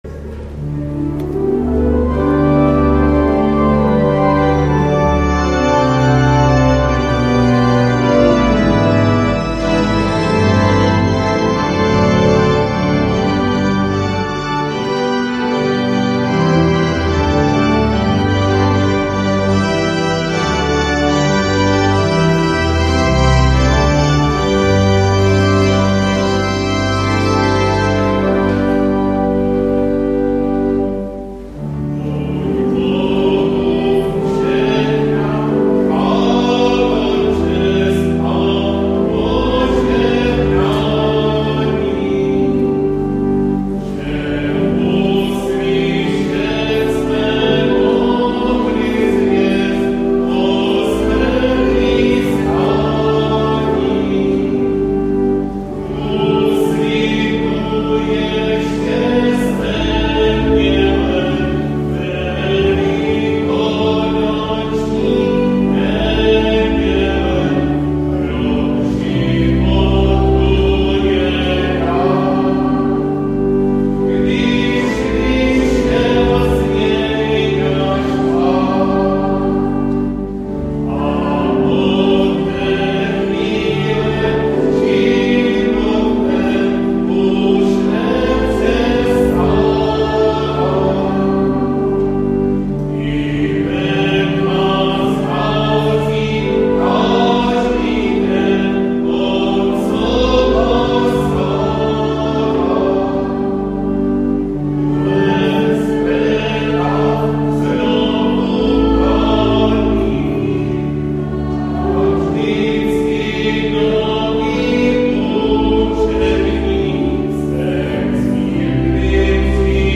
Přehled záznamů bohoslužeb 18.4.